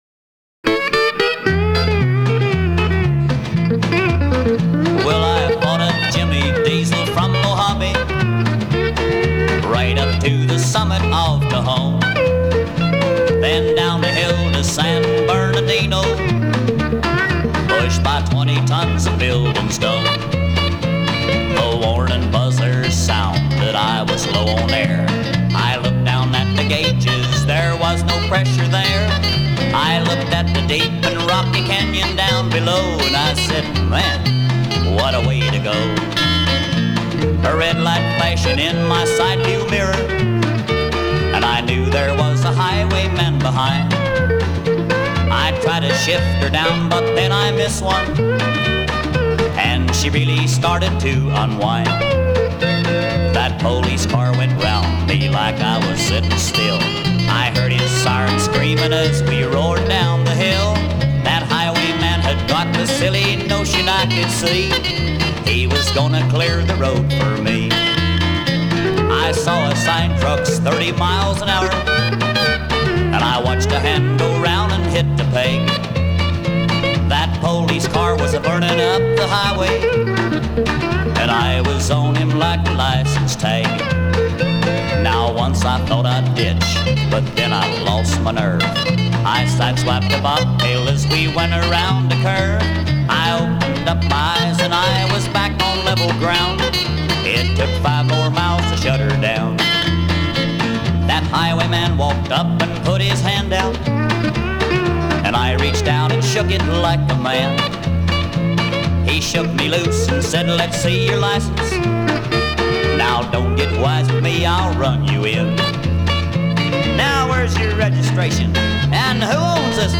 It contains some really awesome steel playing by
pedal steel
precise palm blocking and detailed intentional note slurs
a huge library of sound effects including the siren